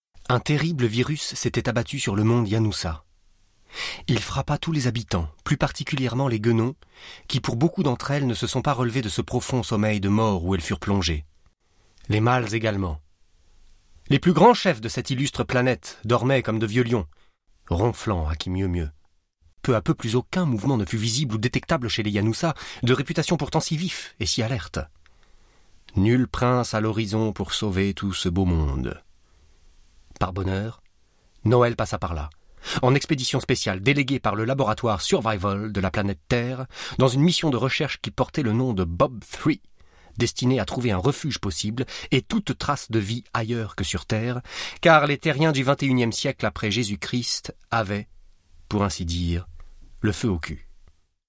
young, dynamic, sport, colourful voice, frenche , französisch, deutsch mit Akzent, English with french accent
Sprechprobe: Werbung (Muttersprache):
young, dynamic and colourful voice